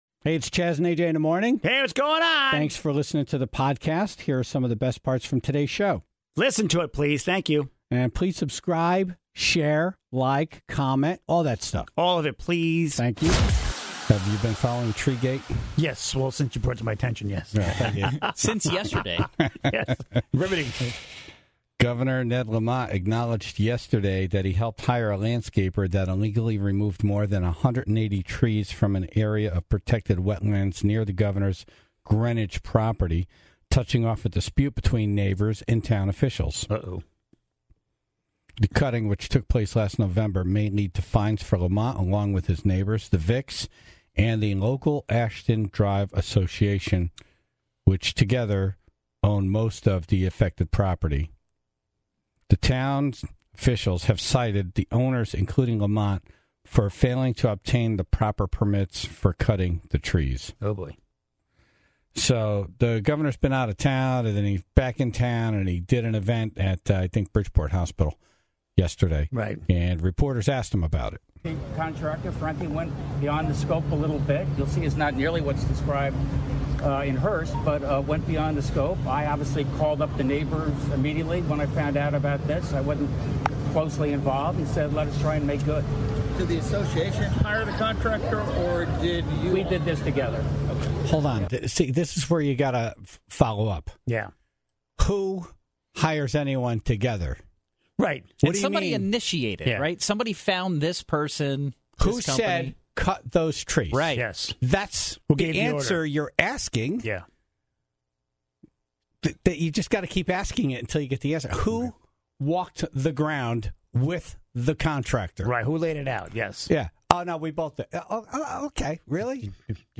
(0:00) Dumb Ass News - The sound of a man falling from the sky, after his homemade flying apparatus failed in midair.